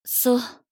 大人女性│女魔導師│リアクションボイス│商用利用可 フリーボイス素材 - freevoice4creators
悲しむ